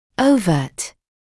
[əu’vɜːt], [‘əuvɜːt][оу’вёːт], [‘оувёːт]открытый; очевидный, явный